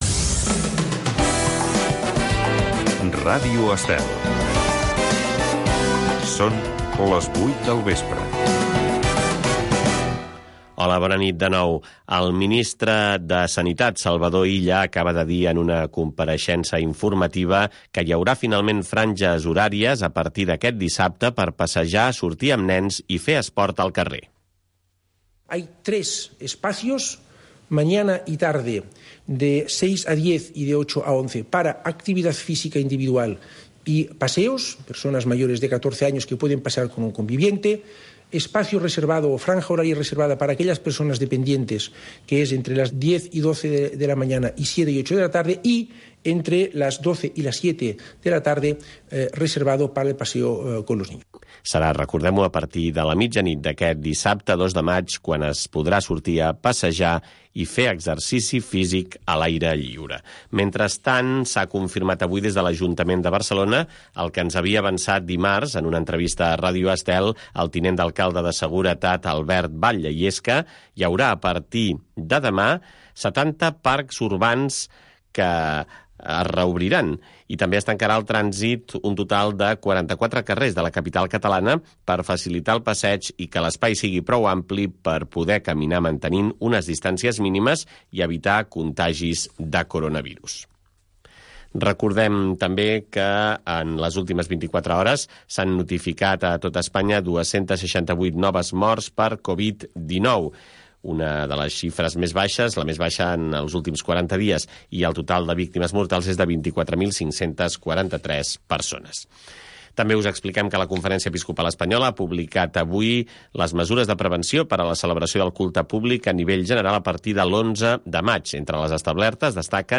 Plusvàlua. Un programa amb entrevistes i tertúlia sobre economia amb clau de valors humans, produït pel CEES